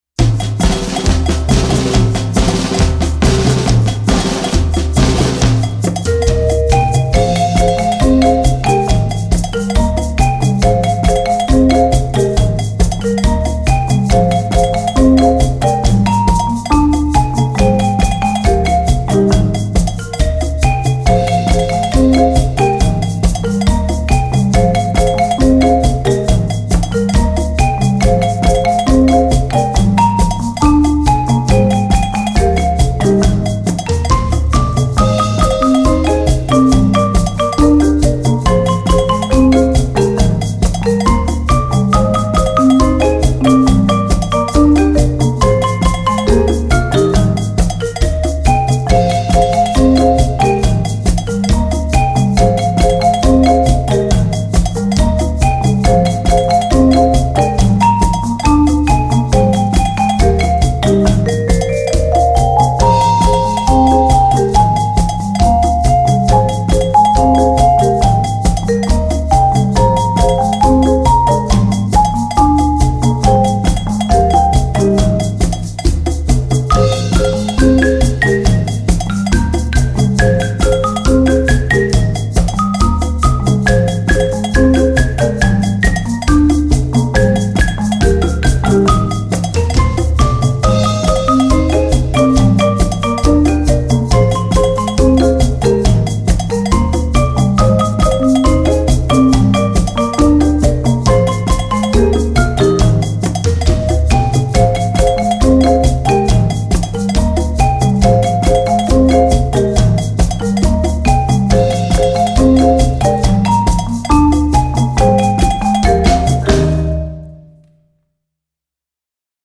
Instrumentalnoten für Schlagzeug/Percussion